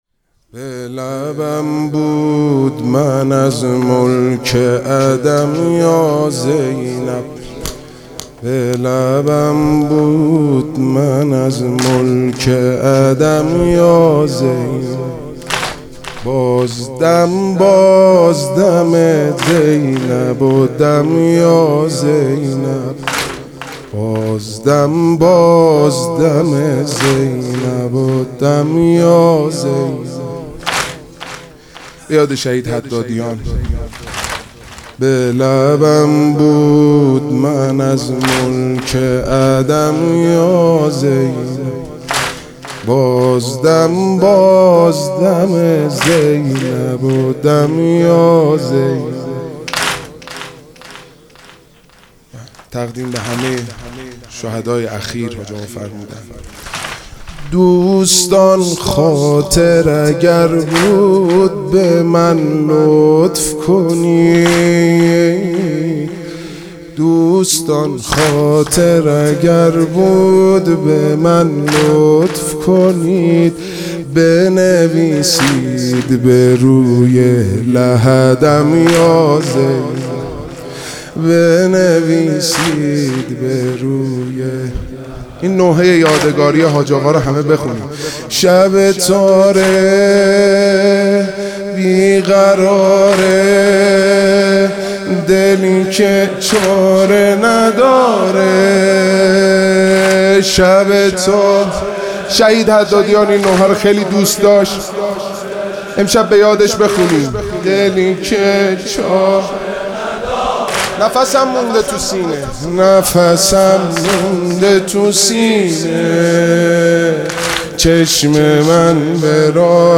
واحد: به لبم بود من از ملک عدم یا زینب